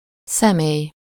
Ääntäminen
IPA: [pɛɾsɔːn]